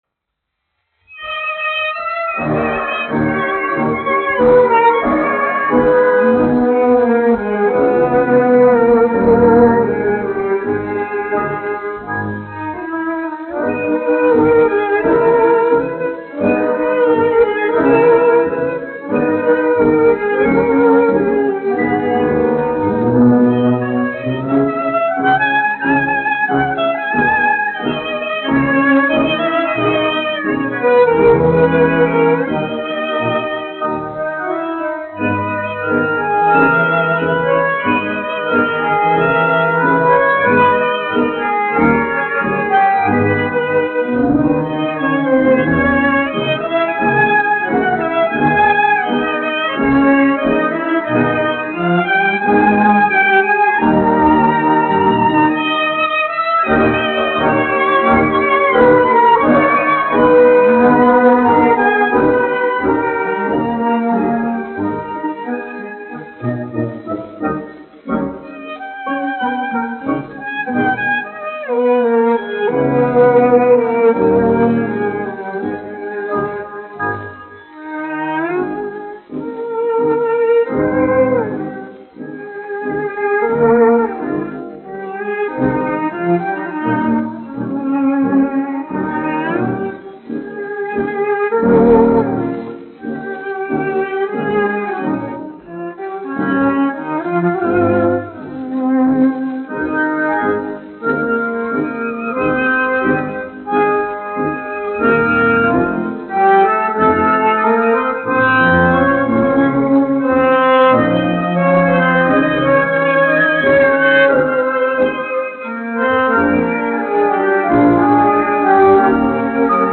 1 skpl. : analogs, 78 apgr/min, mono ; 25 cm
Deju orķestra mūzika
Skaņuplate